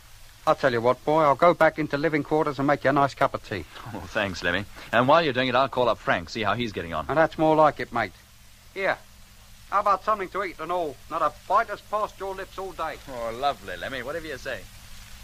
In dit eerste fragment uit deel 12 hoor ik een verschil in de wijze waarop Lemmy in de Britse versie thee en wat eten aanbiedt aan Jet en de wijze waarop Jimmy dat doet aan Jeff in de Nederlandse versie. De tekst is hetzelfde, maar intonatie maakt het toch anders.